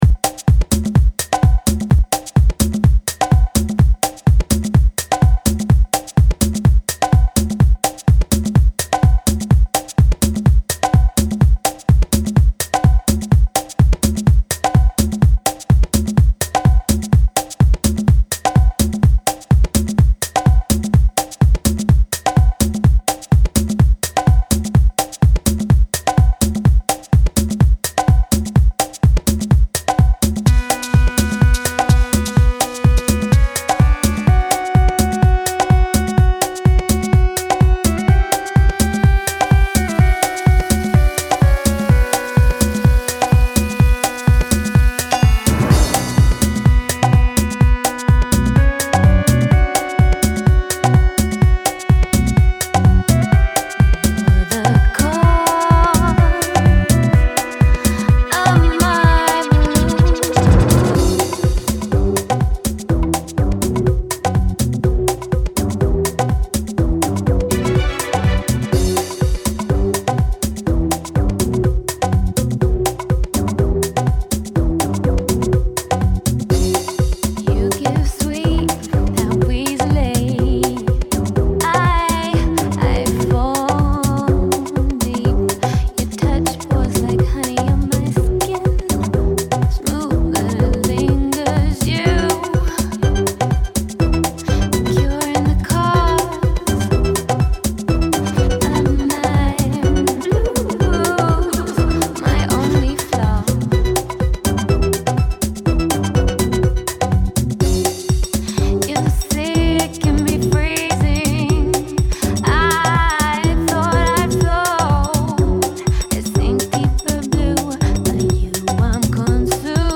risking-my-heart-an-afro_deep-house-journey.mp3